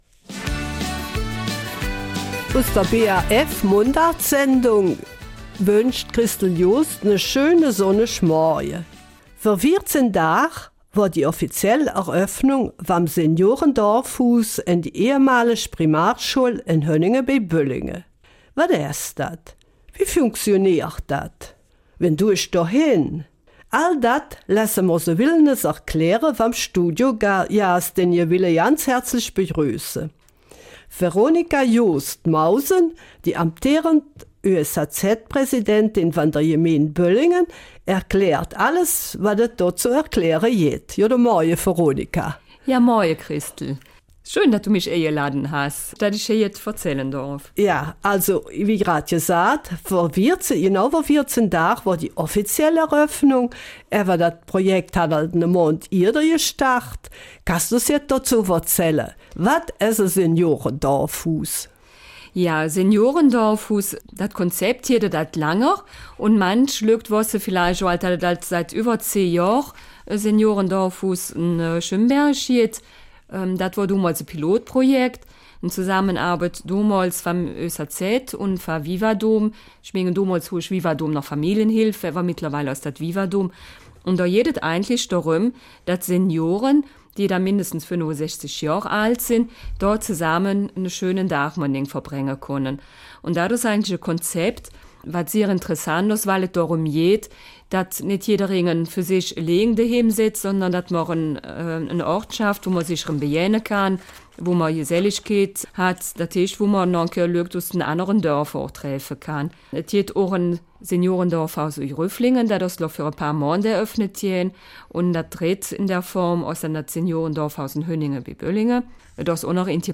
Eifeler Mundart - 15.